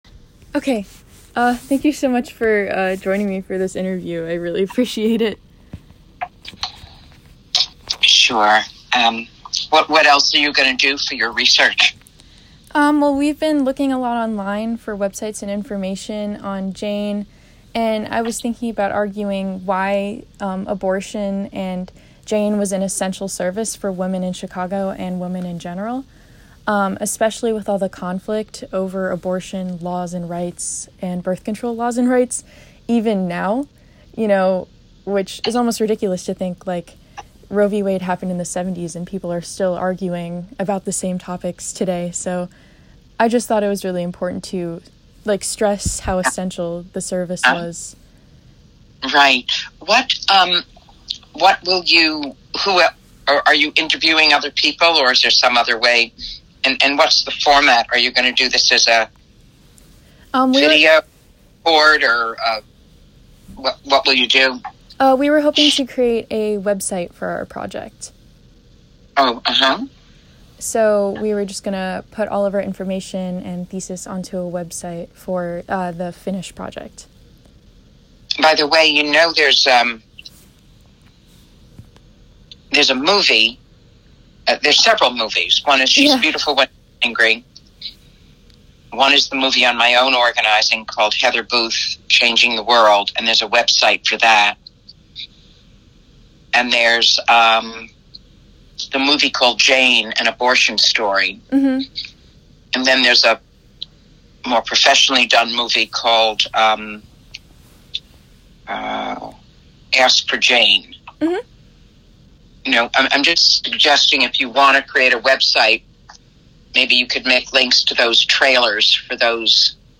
Chicago History Fair interview on JANE
chicago-history-fair-interview-on-jane